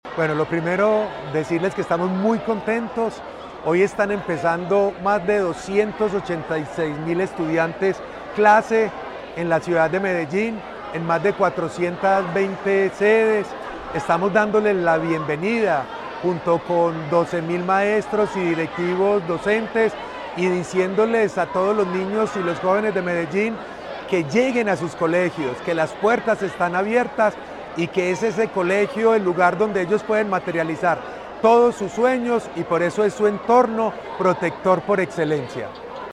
Palabras de Luis Guillermo Patiño Aristizábal, secretario de Educación Más de 290.000 alumnos de las instituciones oficiales del Distrito regresaron este lunes a las aulas para dar inicio al calendario escolar 2025.